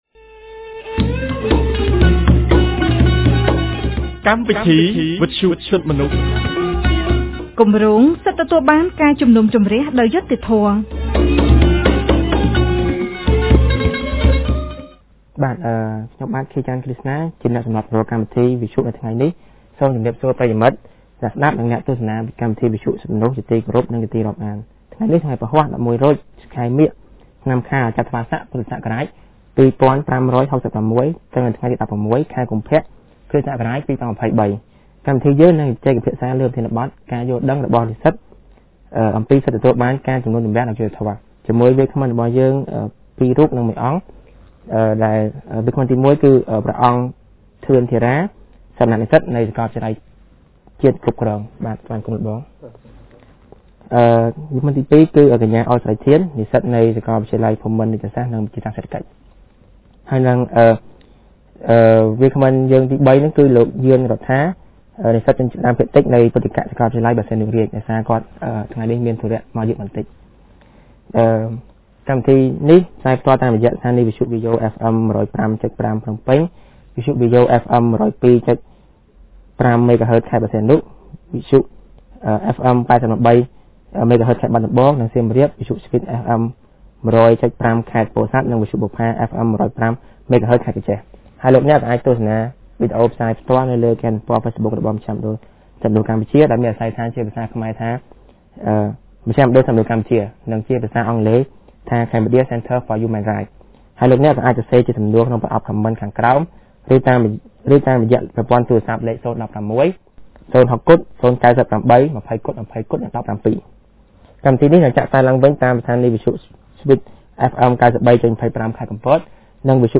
On Thursday 16 February 2023, CCHR’s Fair Trial Rights Project (FTRP) held a radio program with a topic on students' understanding on Fair Trial Rights.